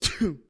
Sneeze 3
Sneeze 3 is a free sfx sound effect available for download in MP3 format.
Sneeze 3.mp3